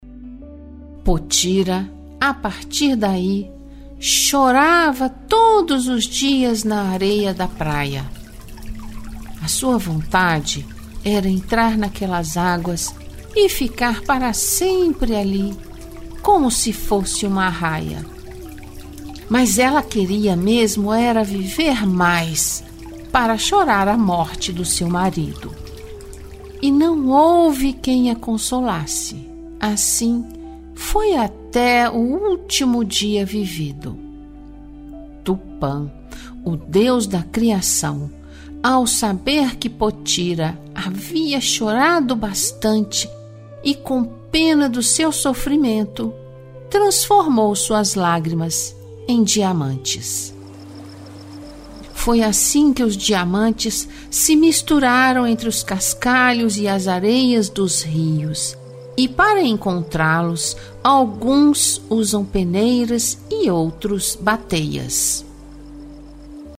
Audiolivro – A lenda do diamante: recontada em versos